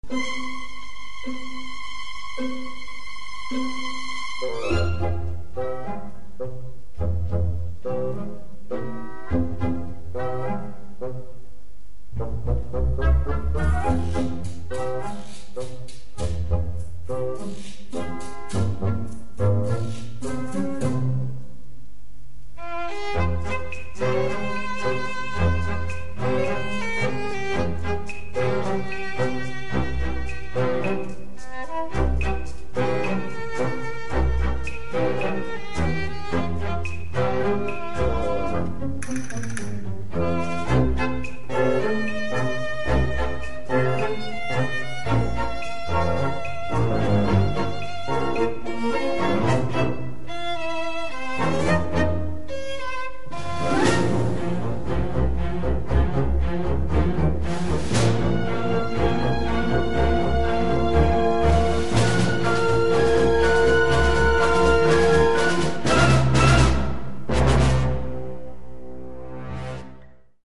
Clip from end credits
Score mixed at Linwood Studios